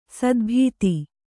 ♪ sadbhīti